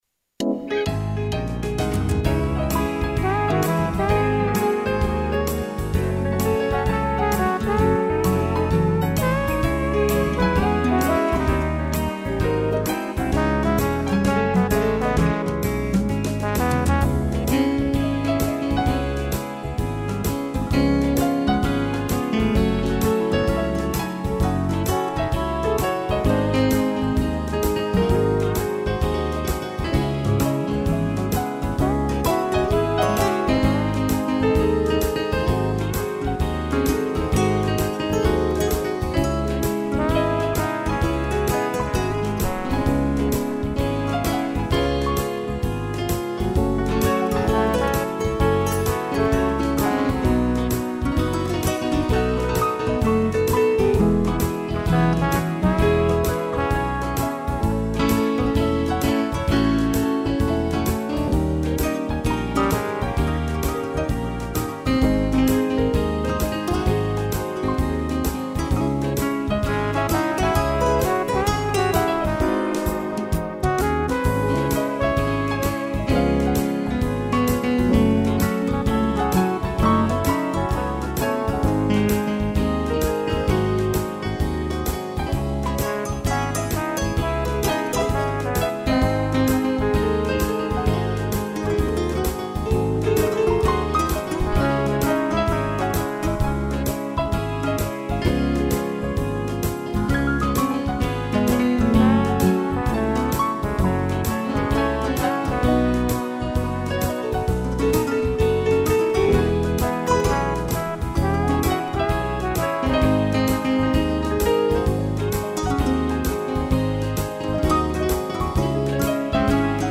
violão
Piano e trombone (instrumental